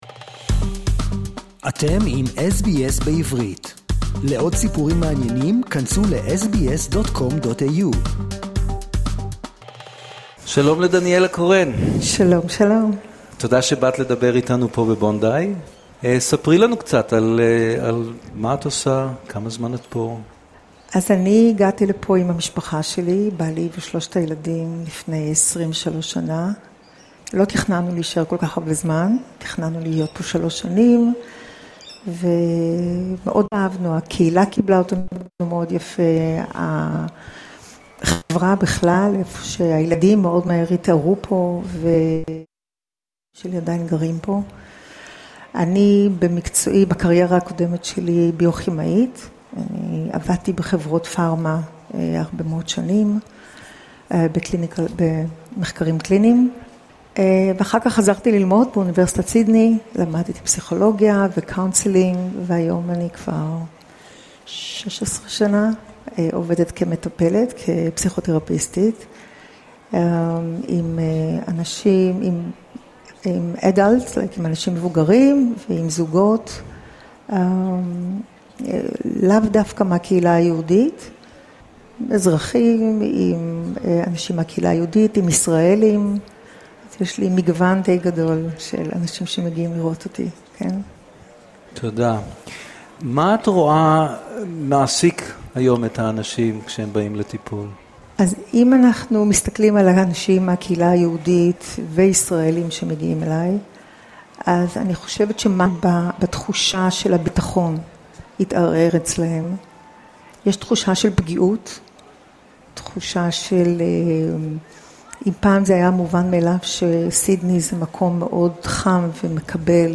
בשבת האחרונה, ב-21 במרץ, כחלק משבוע ההרמוניה, קיימה SBS סדרה מיוחדת של שידורים רב-לשוניים בביתן בונדאי לציון אירוע שנתי זה, הנמשך שבוע שלם, וגם את היום הבינלאומי למיגור האפליה הגזעית.